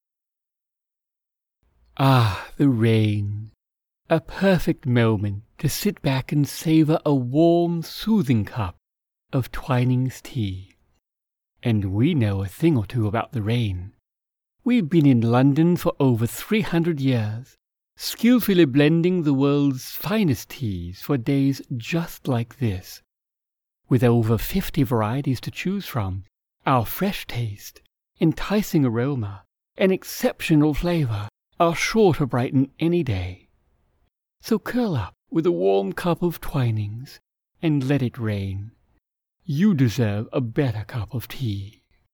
Mature Adult, Adult
Has Own Studio
My voice can be described as deep, smooth, fatherly, and kind, with an authoritative, story-teller vocal style.